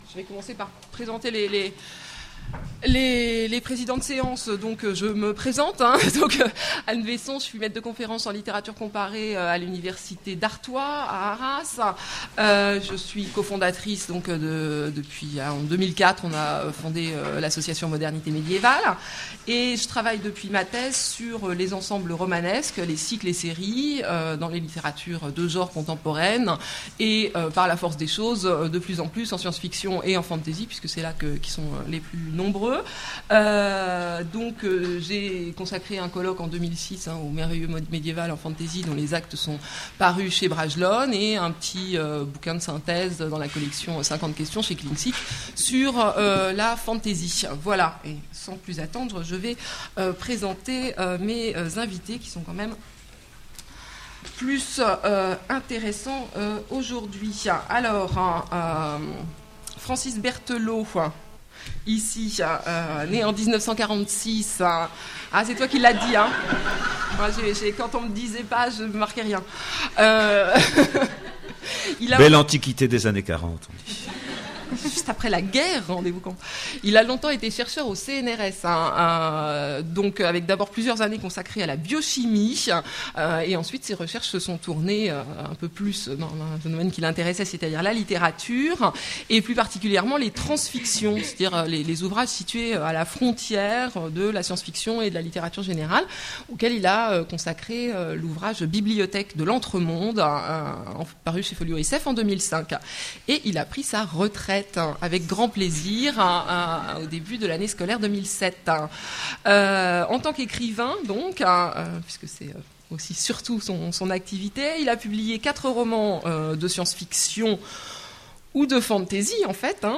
Journée de la fantasy - Conférence : Aux marges du genre
I. La constellation des auteurs 2) Aux marges du genre : table ronde